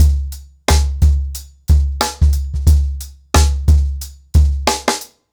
TrackBack-90BPM.43.wav